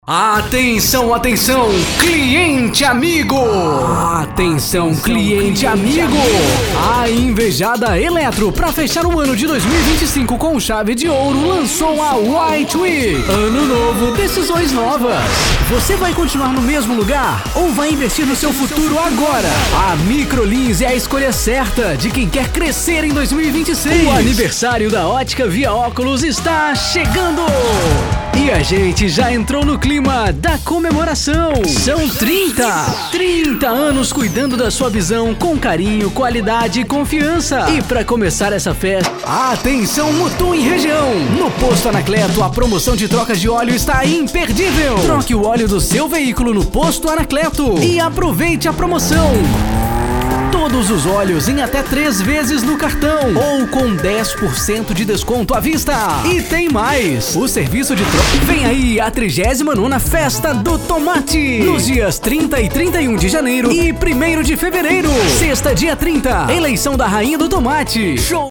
Demo Animada: